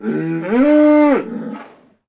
Index of /HCU_SURVIVAL/Launcher/resourcepacks/HunterZ_G4/assets/minecraft/sounds/mob/cow
say2.ogg